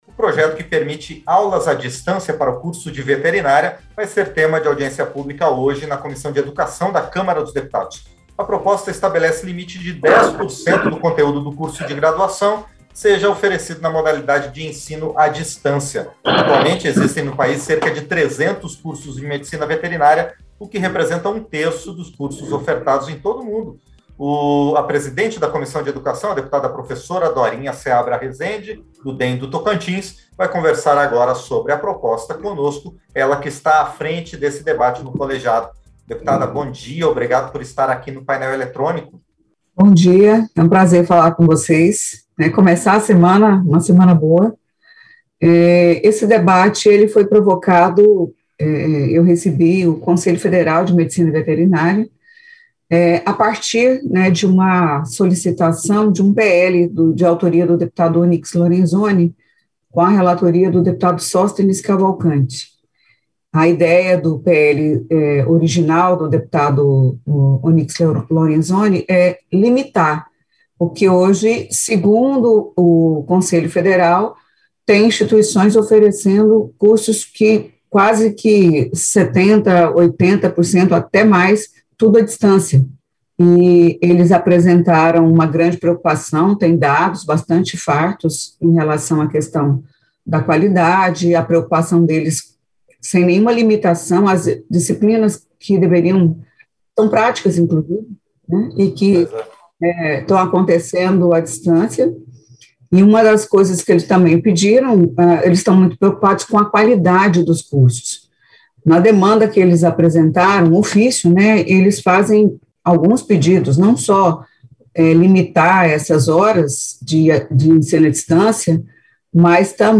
Entrevistas - Dep. Professora Dorinha Seabra Rezende (DEM-TO)